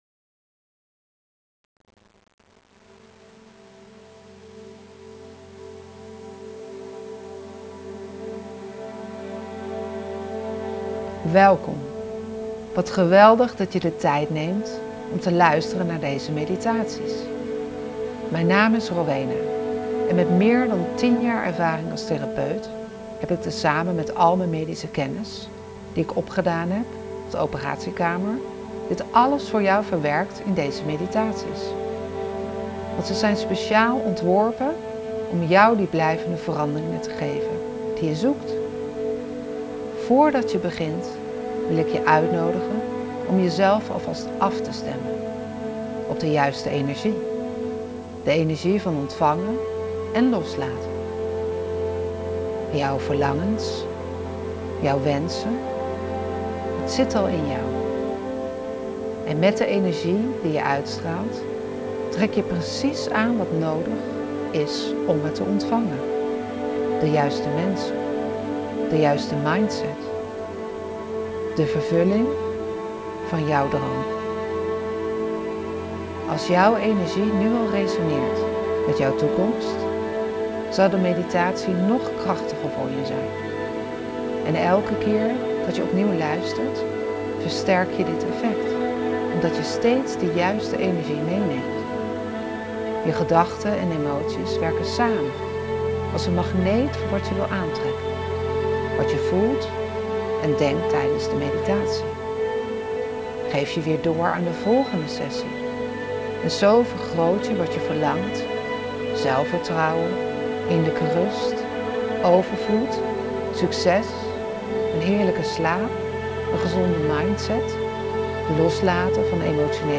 1. Introductie meditatie